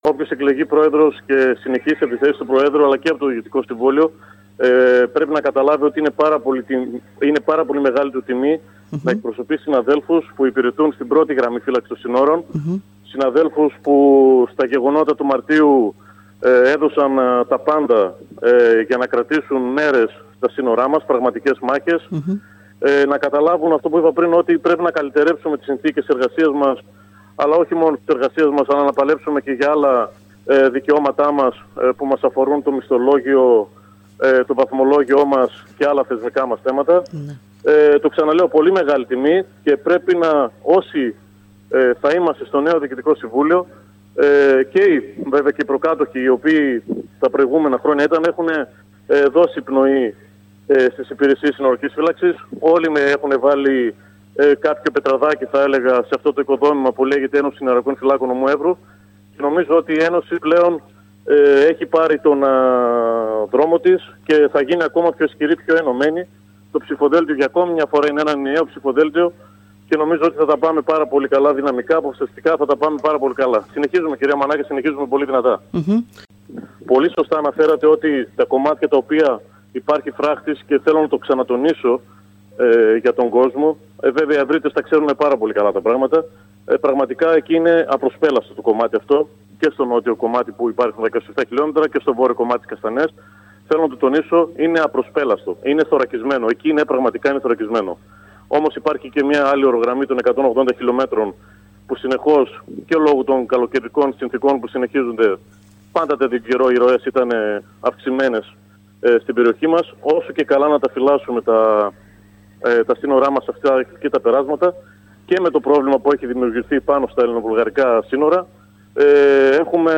Σε δηλώσεις του στην ΕΡΤ Ορεστιάδας